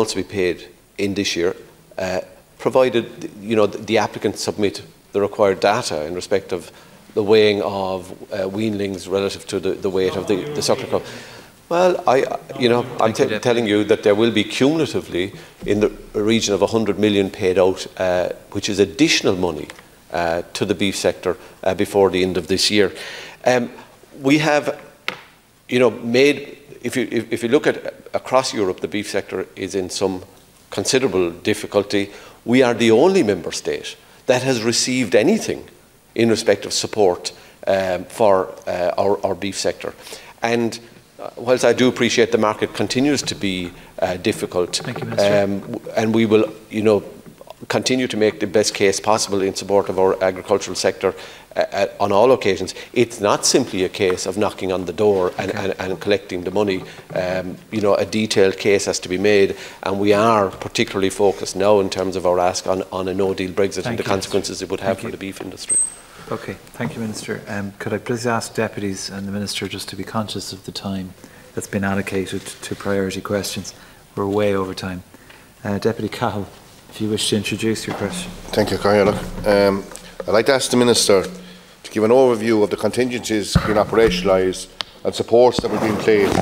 Deputy Charlie McConalogue was speaking yesterday during parliamentary questions as he raised the ongoing income crisis for beef farmers.
Deputy McConalogue called on Minister Michael Creed to urgently ensure support is given to farmers: